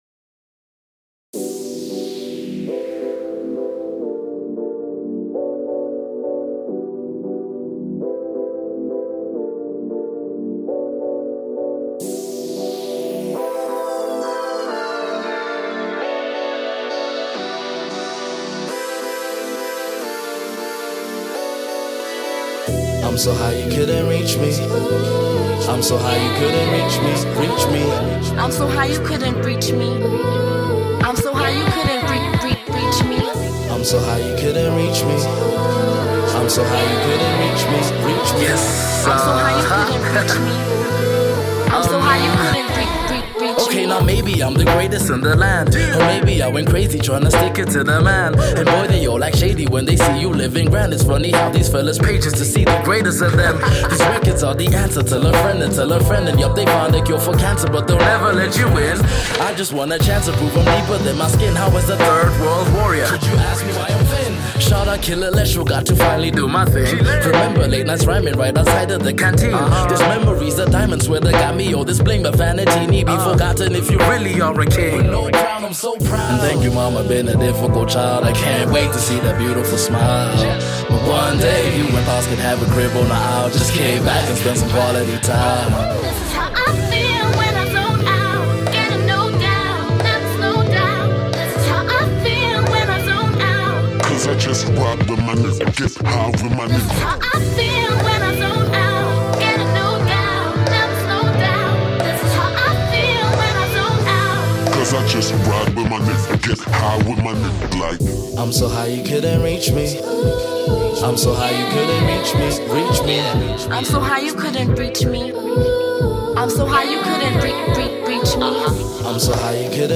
keep putting out solid hiphop materials.